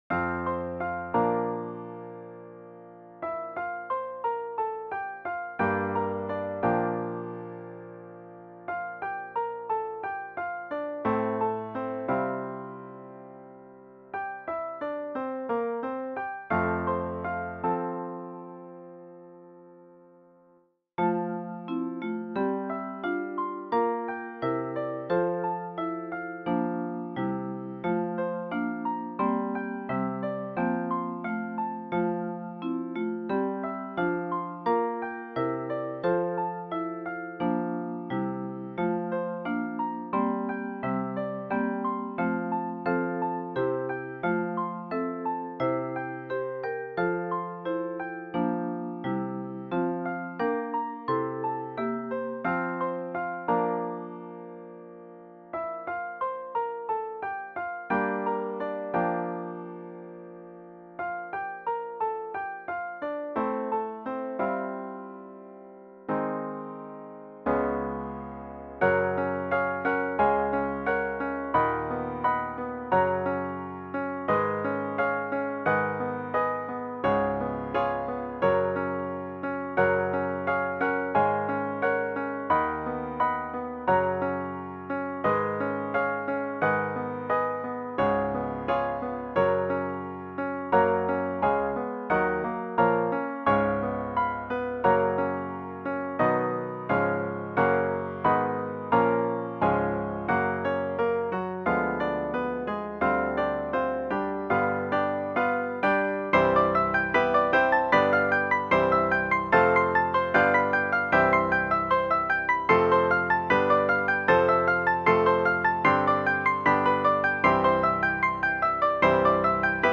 for 3-5 octaves of handbells